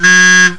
Sons urbanos 35 sons
buzina3.wav